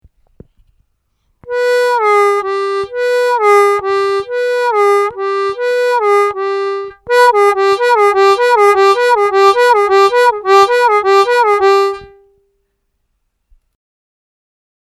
Научиться играть бенд в сочетании с другими нотами таким образом, чтобы другие ноты звучали абсолютно чисто и не бендились.